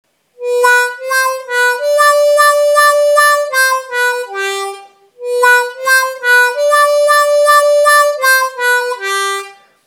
Сыграть музыкальную фразу: -3+4-3-4 _ _ _+4-3-2, на каждой ноте делая по одному эффекту вау-вау.
Нижнее подчеркивание — это повторяющийся эффект вау-вау на той же ноте.